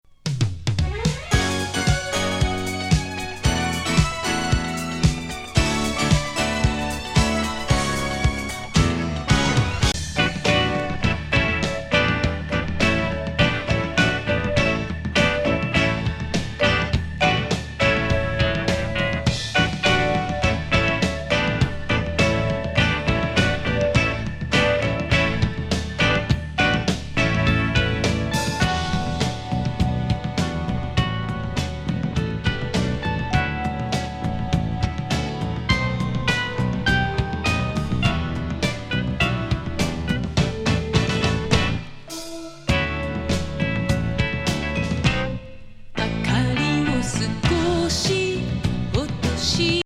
シンセサイザー
トロピカル・ファンカラティーナ